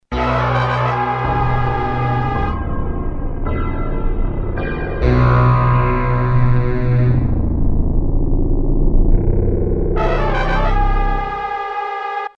This could be very good background music for a video game. The algorithm is based on a 2nd order Markovian probability chain.